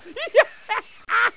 laugh.mp3